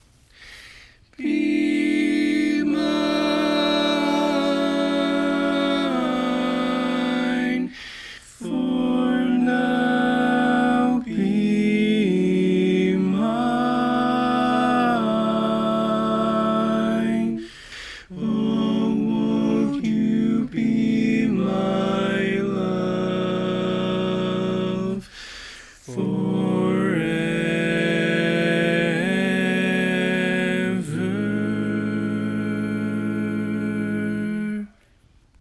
Key written in: A Major
Type: Barbershop